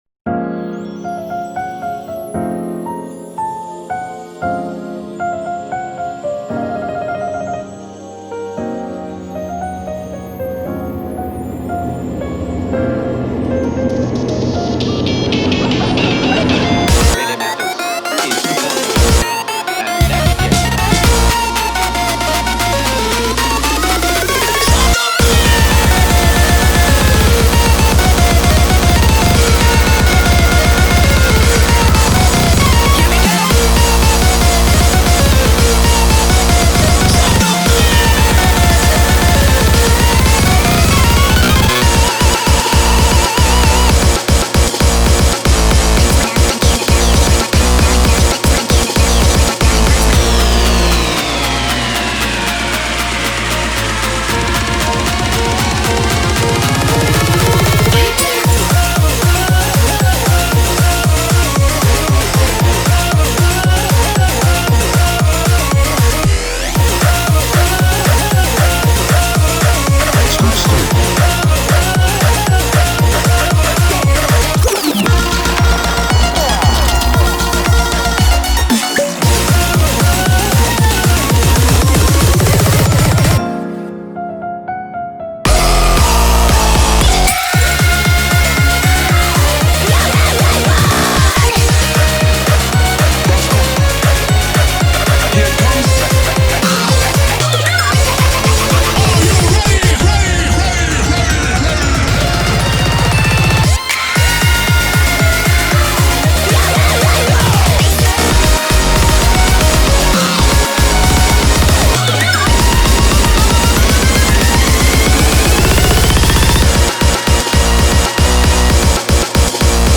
BPM231